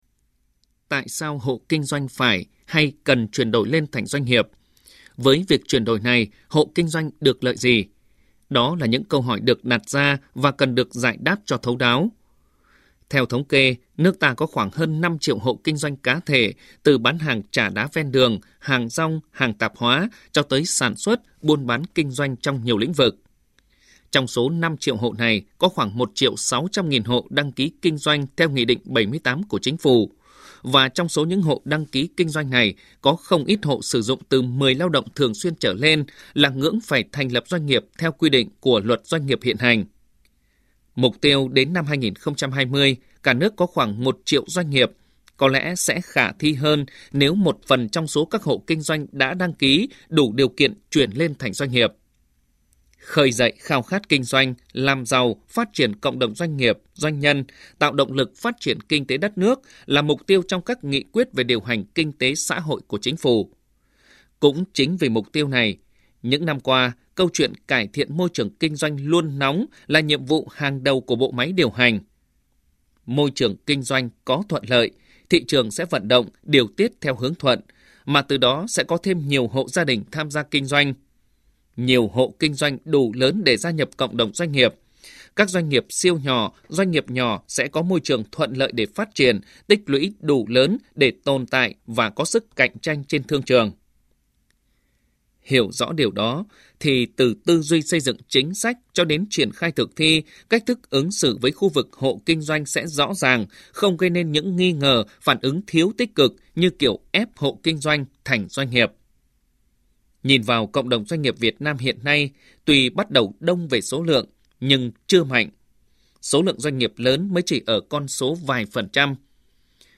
THỜI SỰ Bình luận VOV1